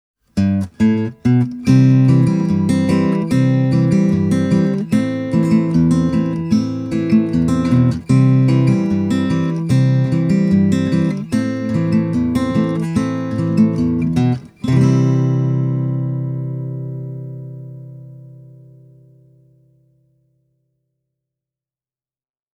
Fender’s Paramount PM-2 AM is a parlour-sized steel-string acoustic (similar in size to C.F. Martin’s size 00) with a 12th fret neck joint.
Don’t get me wrong, though: The Fender PM-2 All Mahogany doesn’t sound thin, or puny, or sharp – its voice is beautiful, well-balanced and warm. It’s just that the tidier bass response will keep the bass register from swamping everything else, unlike when using certain Dreadnought models.
The PM-2 AM is a fantastic player, and it offers you the warm, but open tones, you’d associated with a quality exponent of the 00-size guitar.